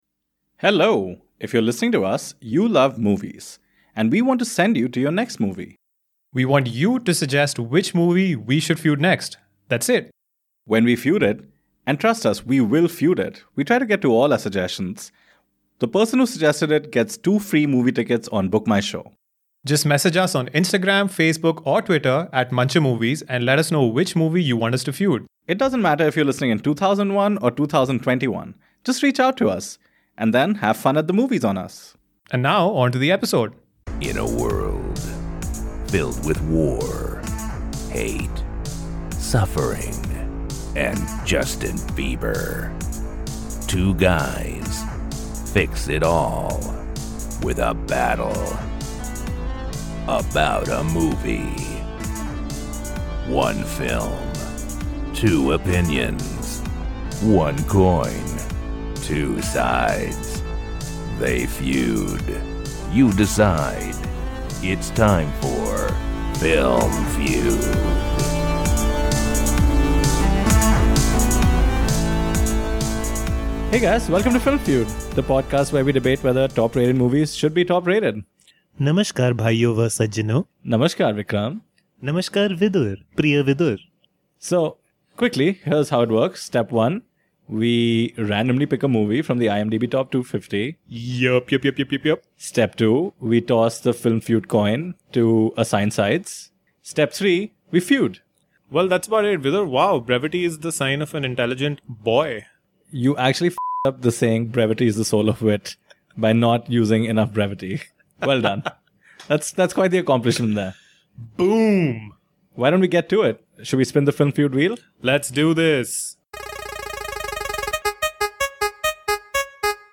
Both coincidentally do atrocious foreign accents at different points.